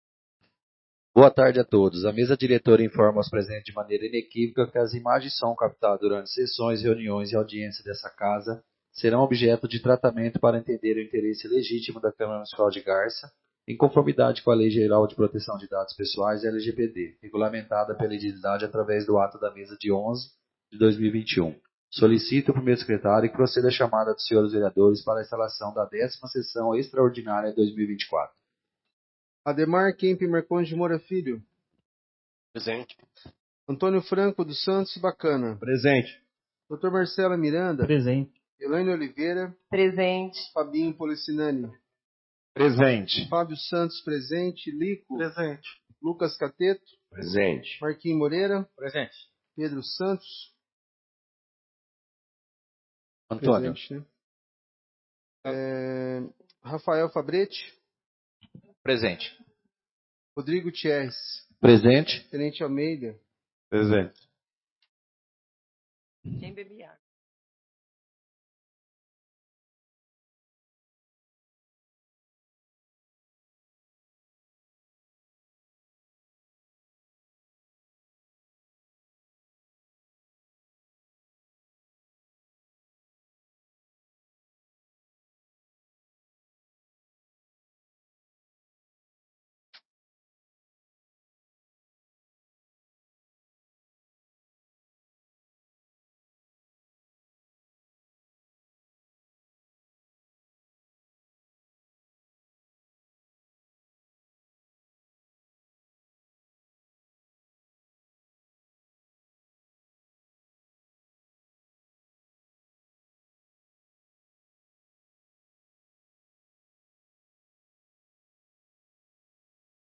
10ª Sessão Extraordinária de 2024